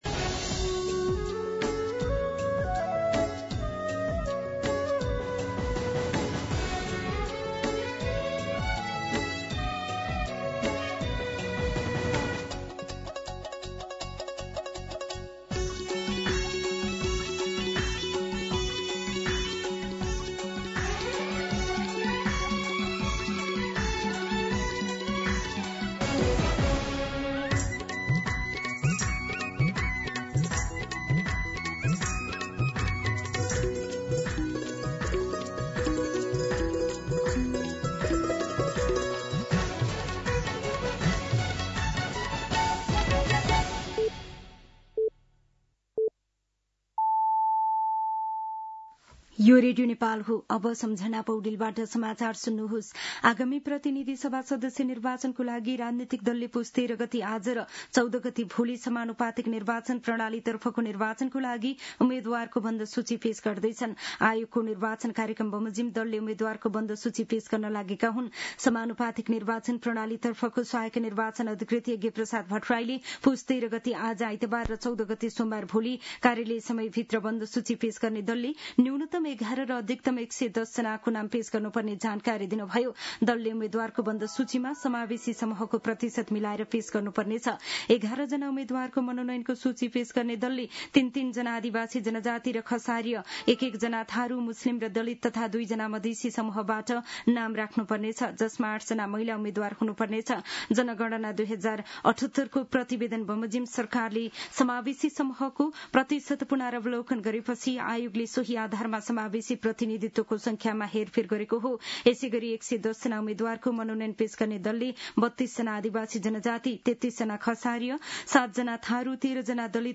मध्यान्ह १२ बजेको नेपाली समाचार : १३ पुष , २०८२